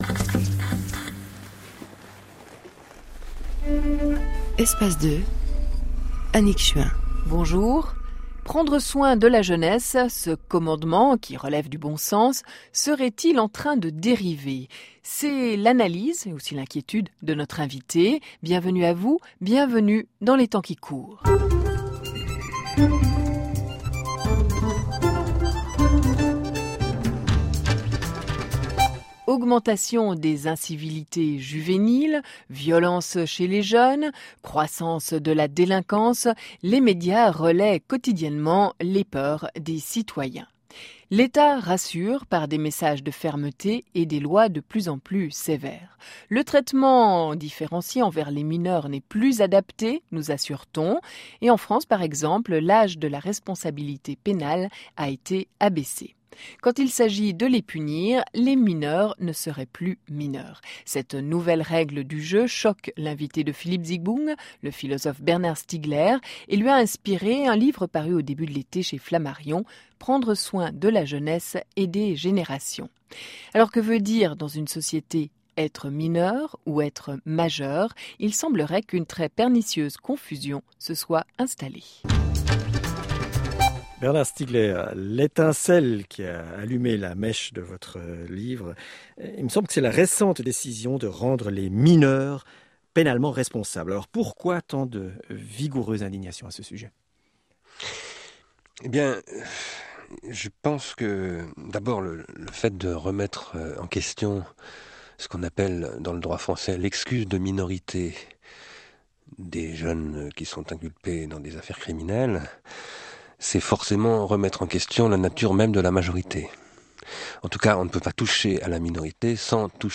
A propos du livre « Prendre soin », dans l’ émission Les temps qui courent sur RSR du 28 Août 2008 .